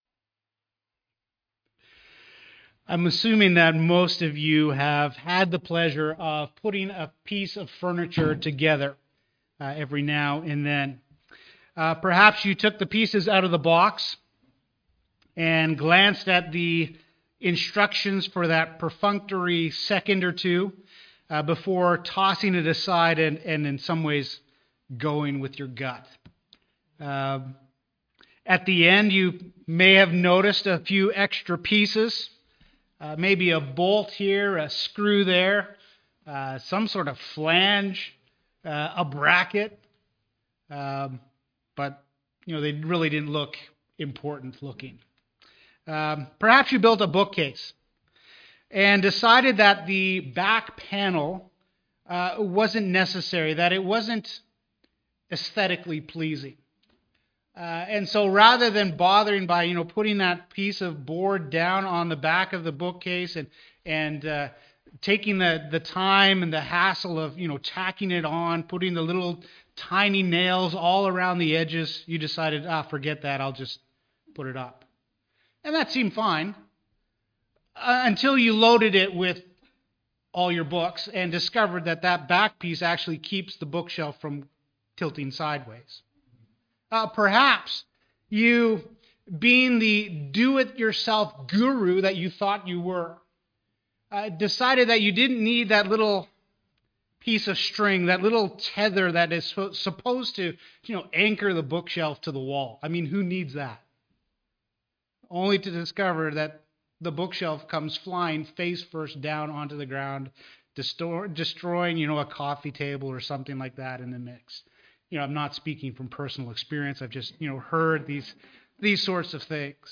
Romans Sermon Series